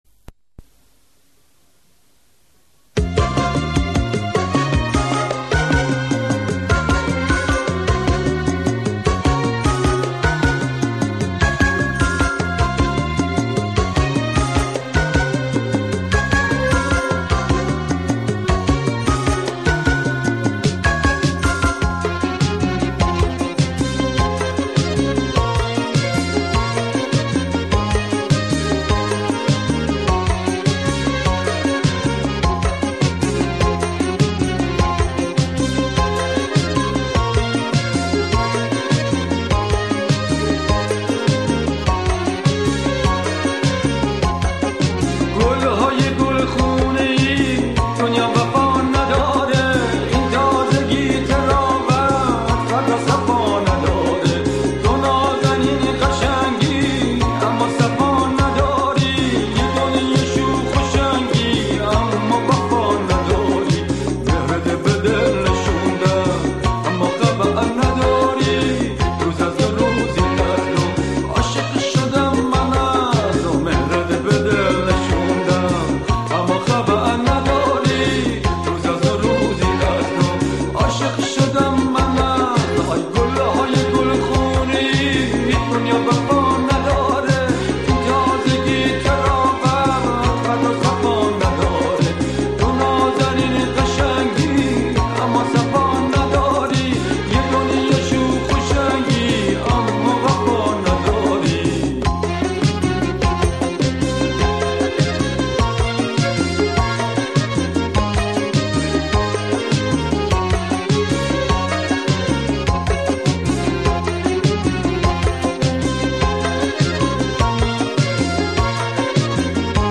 گیتار ۱۲ سیمی
آهنگسازی متأثر از موسیقی راک غربی
موسیقی پاپ ایران